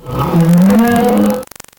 Cri de Frison dans Pokémon Noir et Blanc.